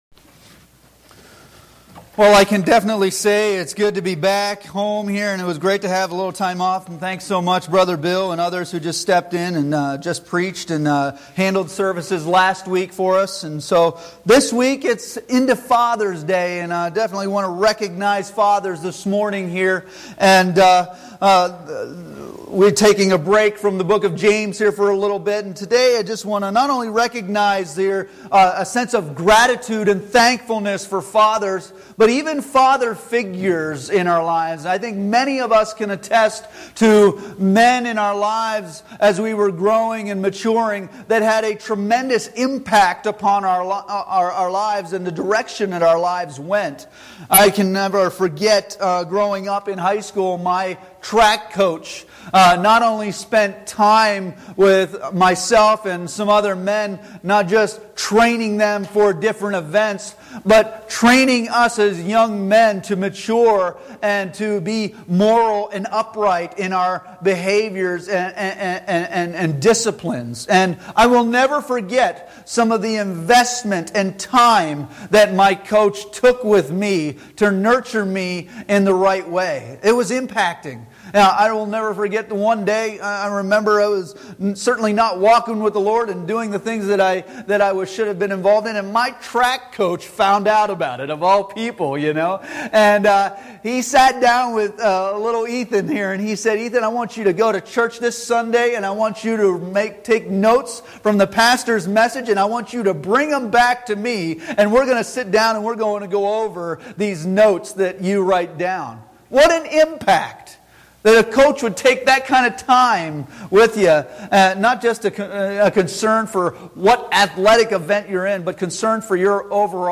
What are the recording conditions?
2 Timothy 1:1-4 Service Type: Sunday Morning Worship Bible Text